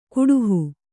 ♪ kuḍuhu